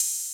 kits/TM88/Closed Hats/Zay Hat.wav at ts